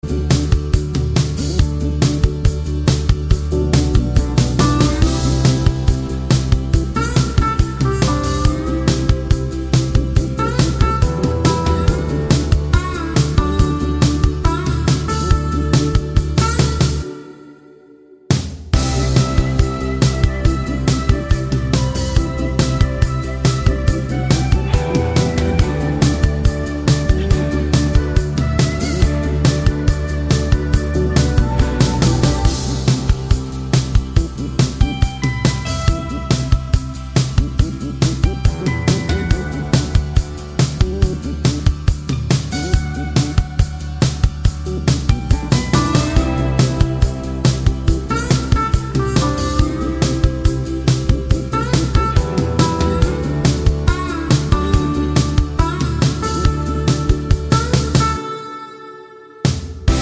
No 2 Part Harmony Pop (2020s) 3:29 Buy £1.50